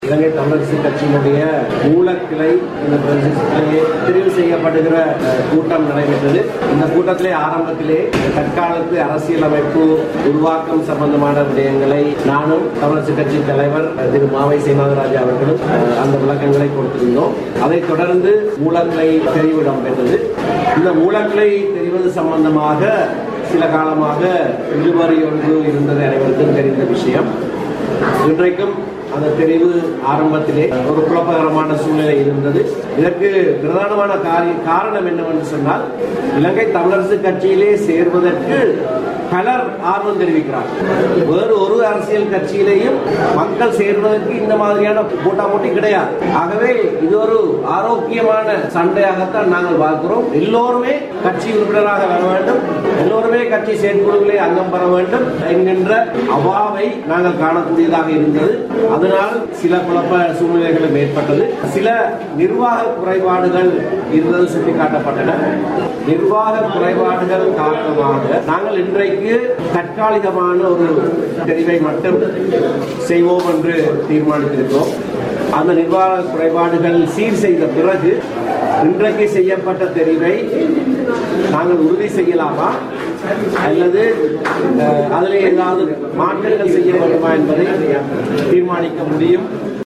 தமிழரசு கட்சியின் புதுக்குடியிருப்பு மூலக்கிளை தெரிவுசெய்கின்ற கூட்டம் நேற்று முல்லைத்தீவில் இடம்பெற்றது.
இந்த சந்தர்ப்பத்தில் ஊடகங்களுக்கு கருத்து தெரிவித்தபோது அவர் இதனை தெரிவித்தார்.